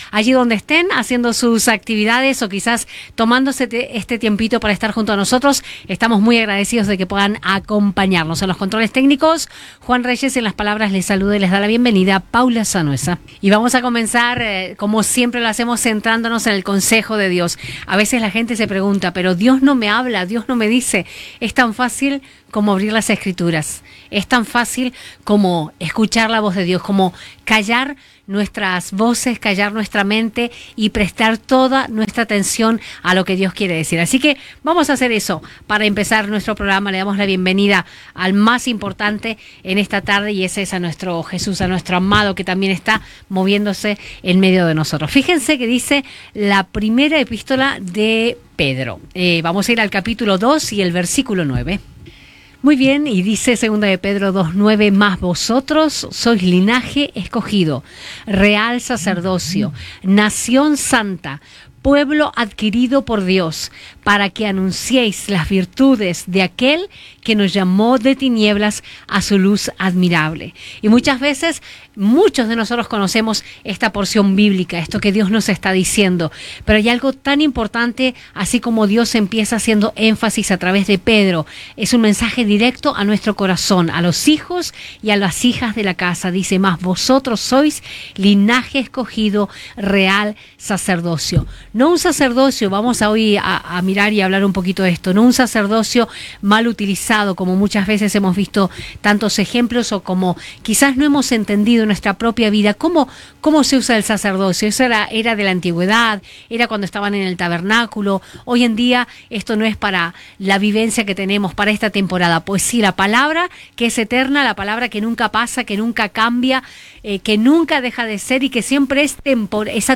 Inici del programa amb la lectura d'un fragment de l'Evangeli
FM